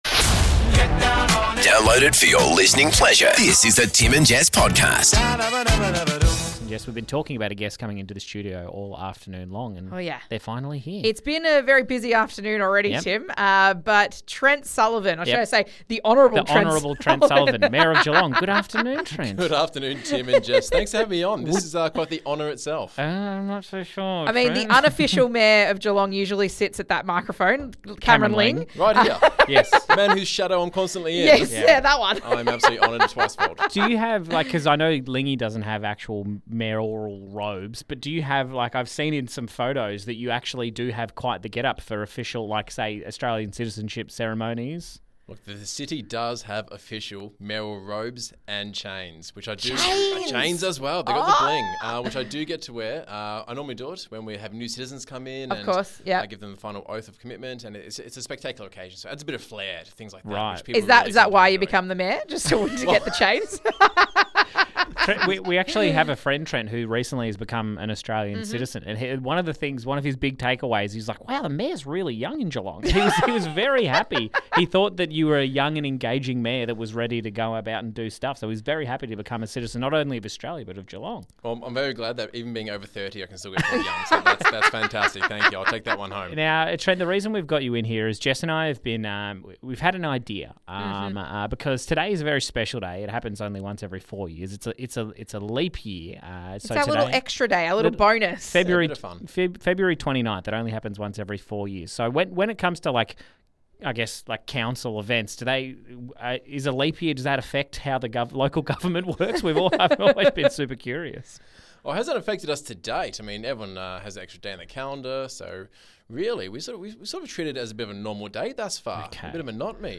- GUEST: The Mayor of Geelong, Trent Sullivan